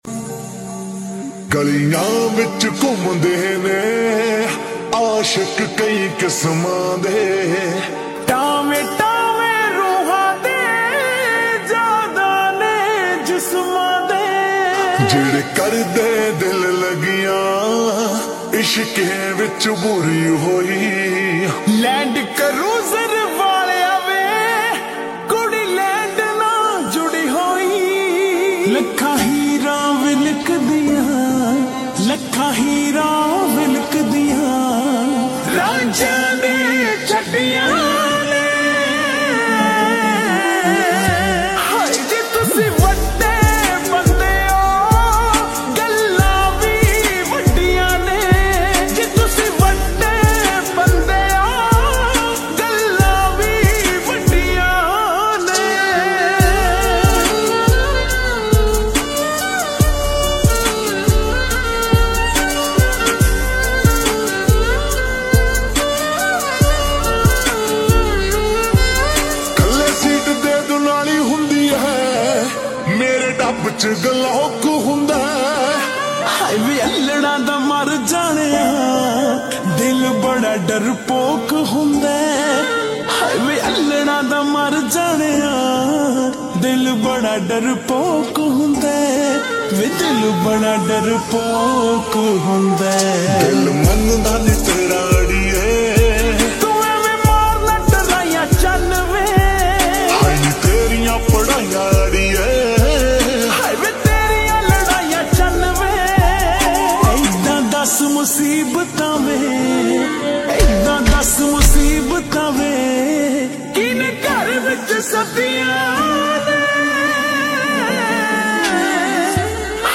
SLOW REVERB SONG